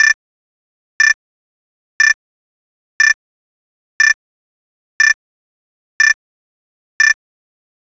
alarm.wav